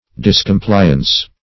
Search Result for " discompliance" : The Collaborative International Dictionary of English v.0.48: Discompliance \Dis`com*pli"ance\, n. Failure or refusal to comply; noncompliance.